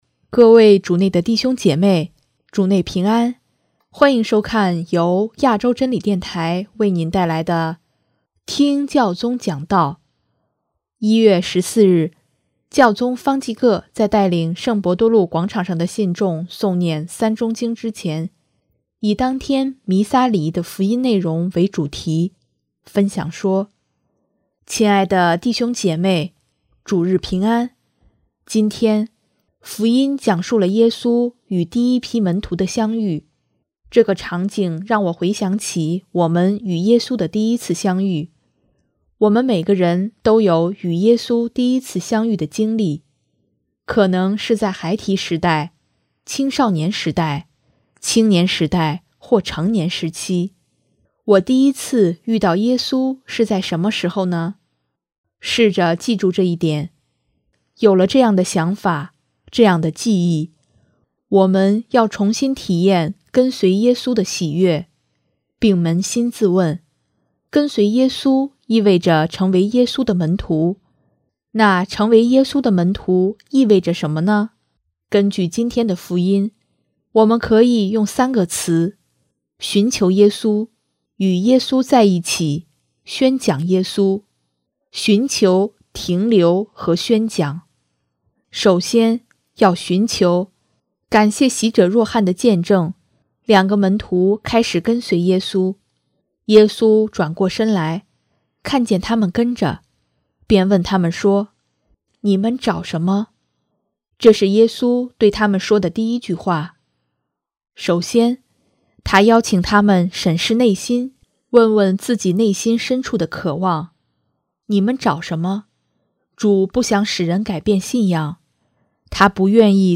【听教宗讲道】|我第一次遇到耶稣是在什么时候？
1月14日，教宗方济各在带领圣伯多禄广场上的信众诵念《三钟经》之前，以当天弥撒礼仪的福音内容为主题，分享说：